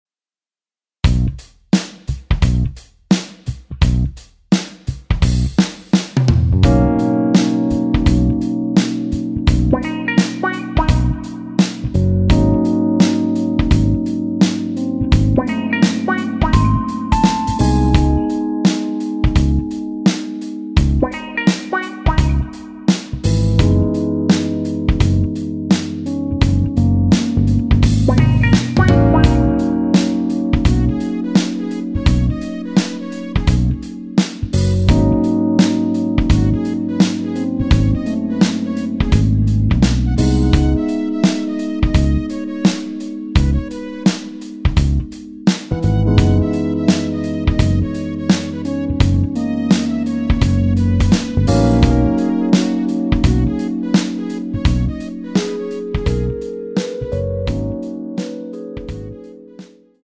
엔딩이 페이드 아웃이라 라이브에 사용 하기좋게 엔딩을 만들어 놓았습니다.(원키 코러스 MR 미리듣기 참조)
◈ 곡명 옆 (-1)은 반음 내림, (+1)은 반음 올림 입니다.
멜로디 MR이라고 합니다.
앞부분30초, 뒷부분30초씩 편집해서 올려 드리고 있습니다.